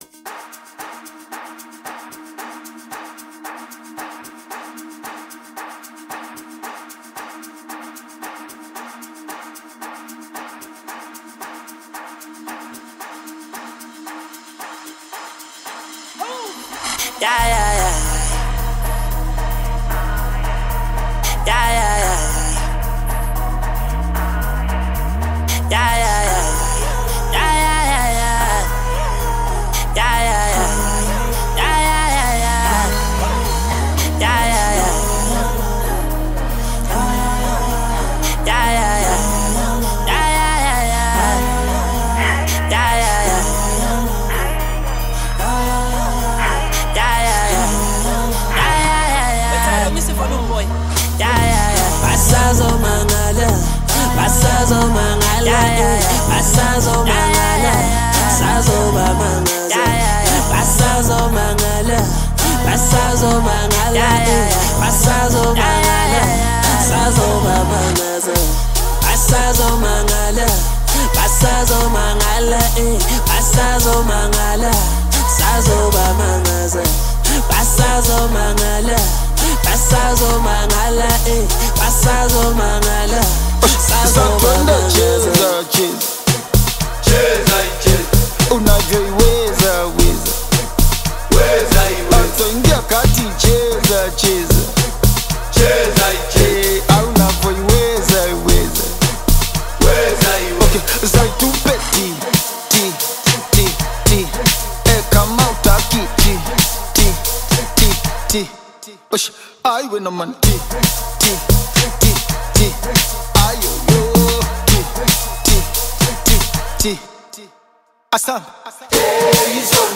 genre-blending Afro-Pop/Amapiano single
club-ready energy
Genre: Bongo Flava